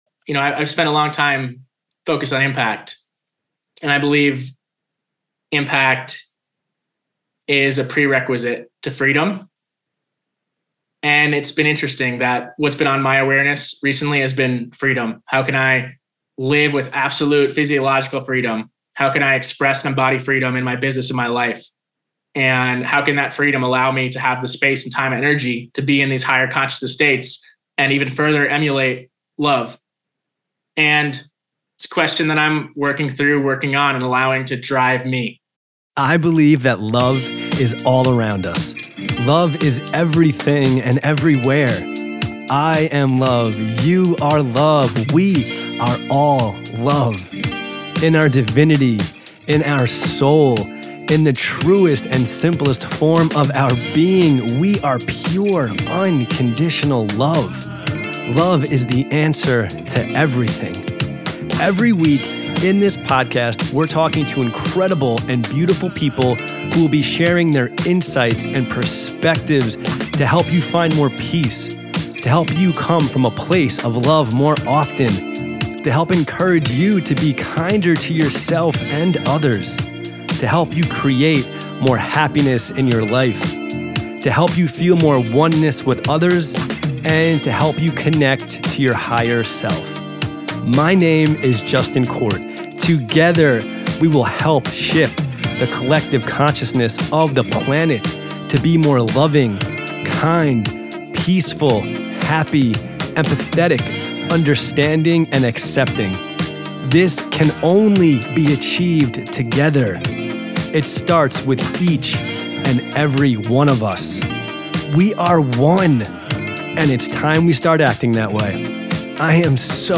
Today’s guest is a beautifully heart centered male who I met in the jungles of Mexico at a retreat.